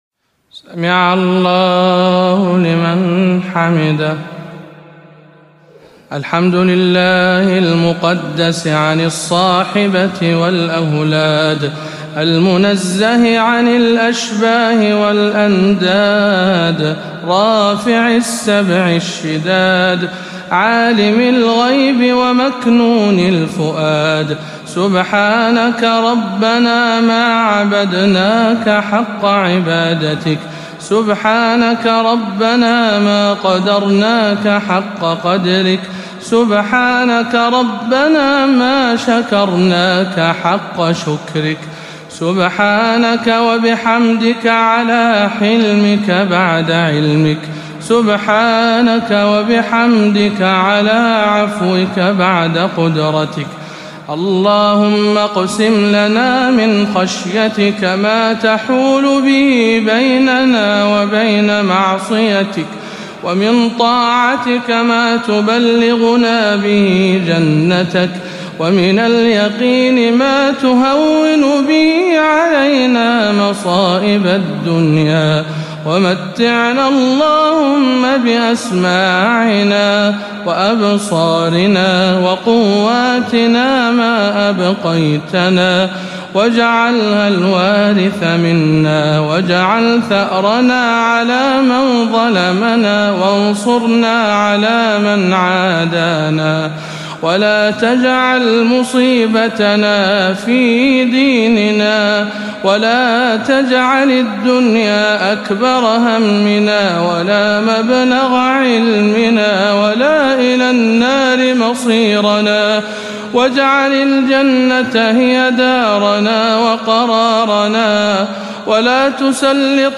أدعية القنوت
رمضان 1438 هـ